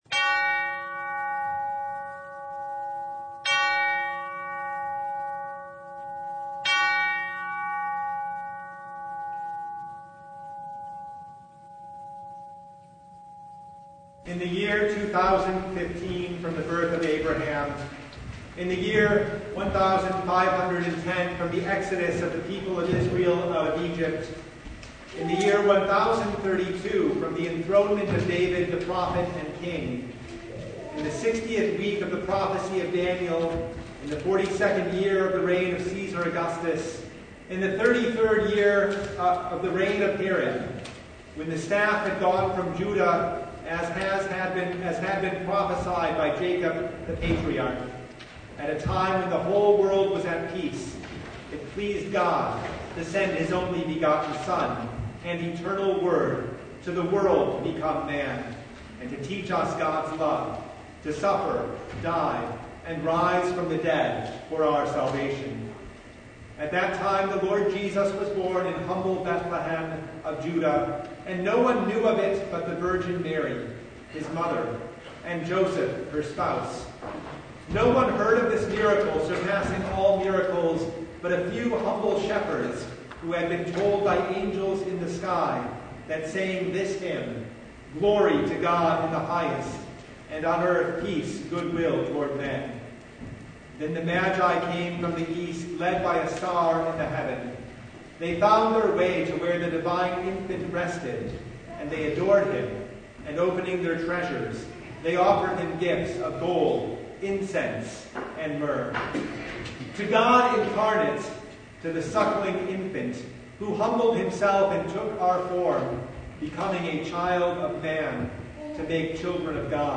Passage: John 1:1-18 Service Type: Christmas Day
Topics: Full Service